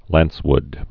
(lănswd)